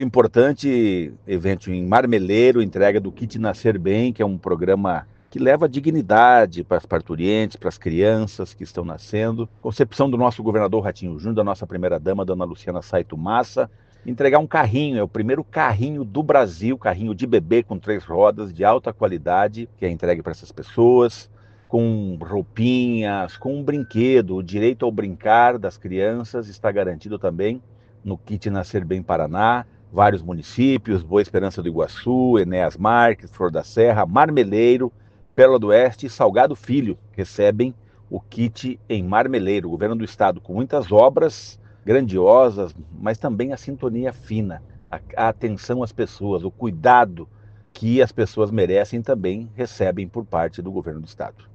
Sonora do secretário secretário do Desenvolvimento Social e Família, Rogério Carboni, sobre o início das entregas do Programa Nascer Bem Paraná em 2026